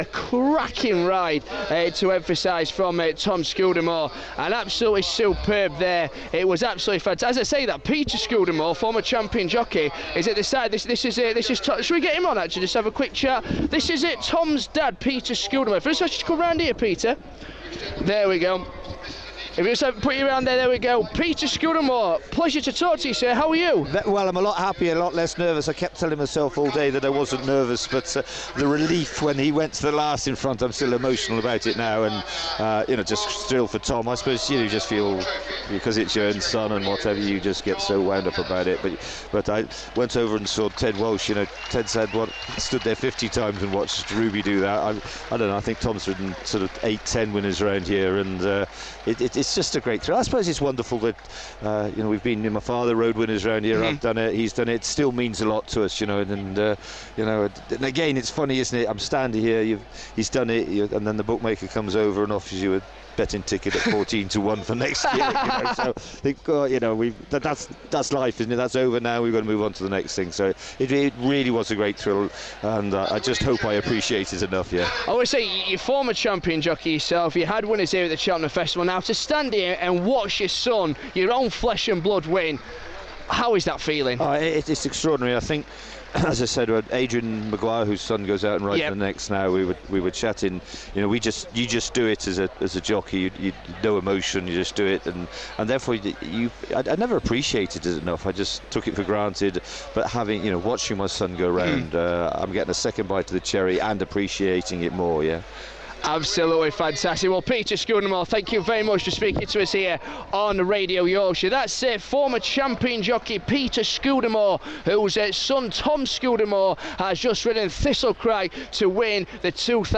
speaks to former champion jockey Peter Scudamore directly after his son Tom's win in the Cheltenham World Hurdle.